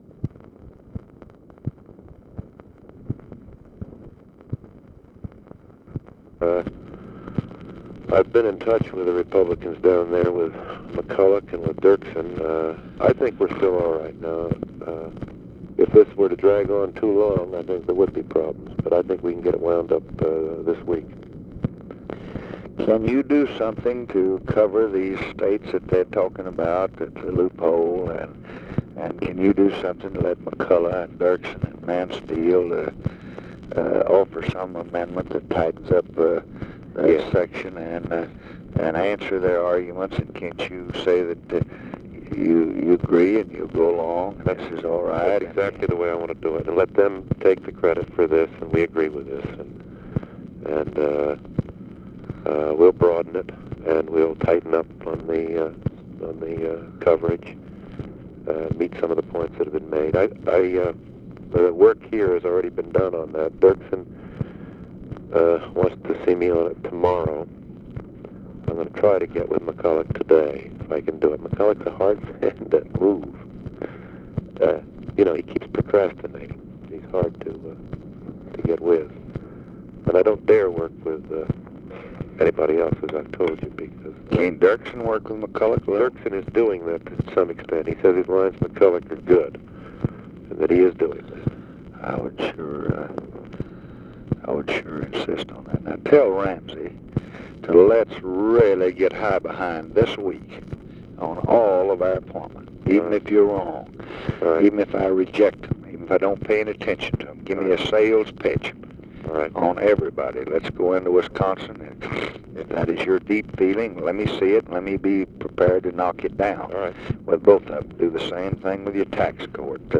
Conversation with NICHOLAS KATZENBACH, March 30, 1965
Secret White House Tapes